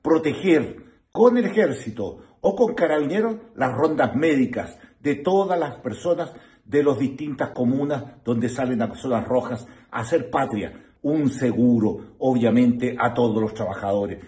Por su parte, el diputado de Renovación Nacional Miguel Mellado planteó que los trabajadores deberían ser acompañados por militares, si es necesario.